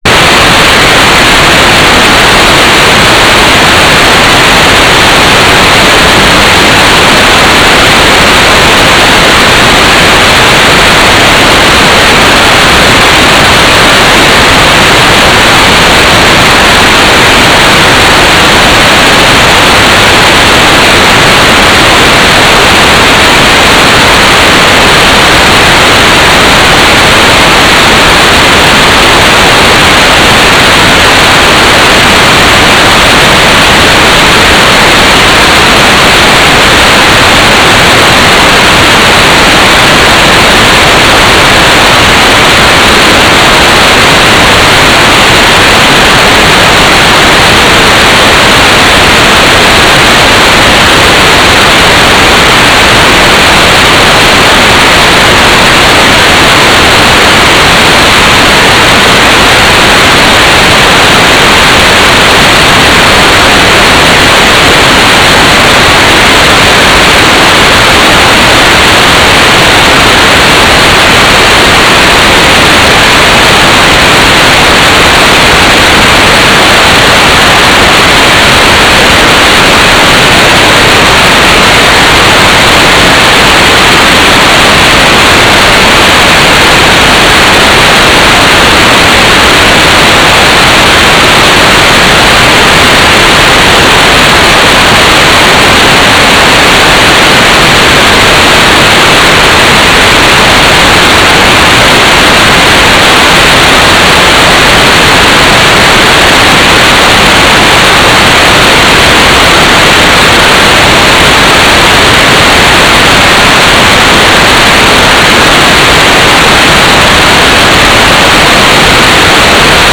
"transmitter_description": "4k8 GMSK",